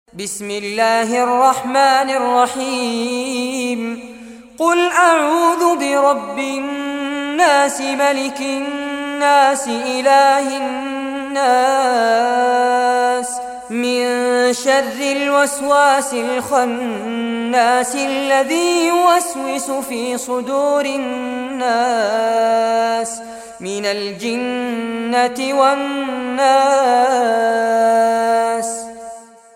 Surah An-Nas Recitation by Sheikh Fares Abbad
Surah An-Nas, listen or play online mp3 tilawat / recitation in Arabic in the beautiful voice of Sheikh Fares Abbad.